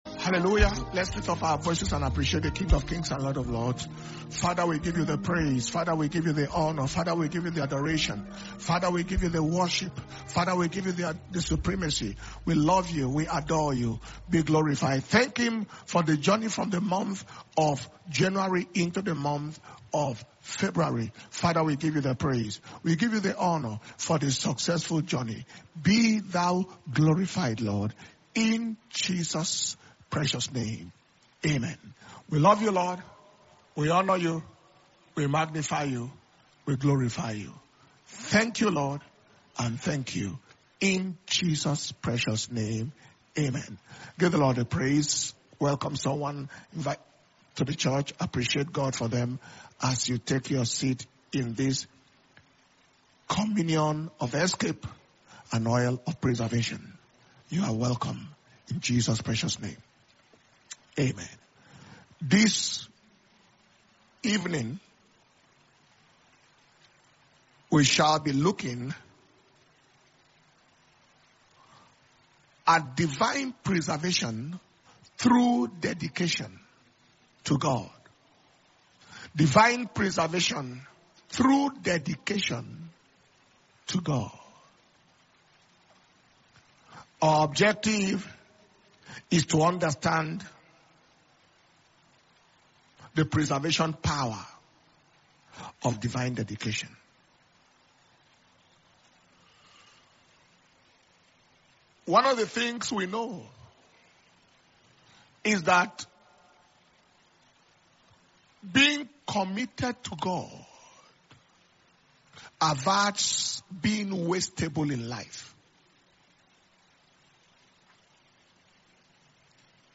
February 2026 Preservation And Power Communion Service